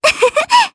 Kirze-Vox-Laugh_jp.wav